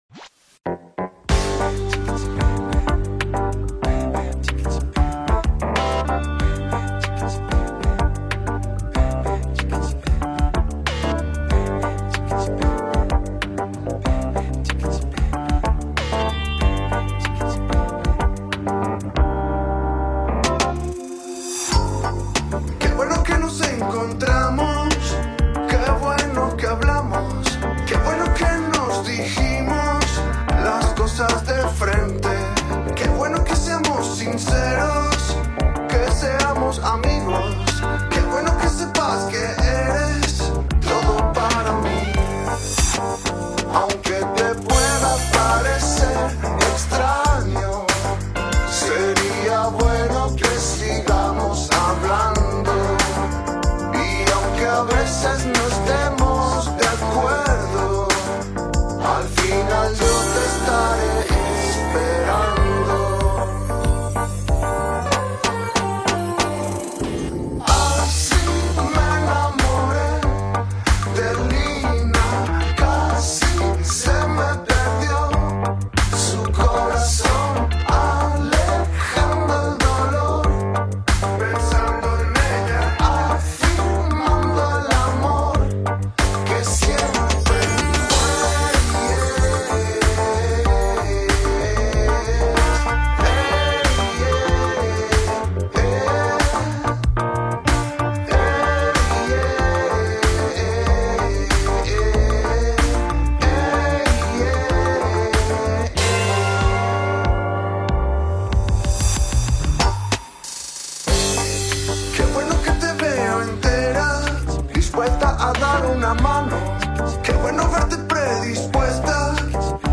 Guitarras, programación, synths y coros
Piano Rhodes
Bajo y voz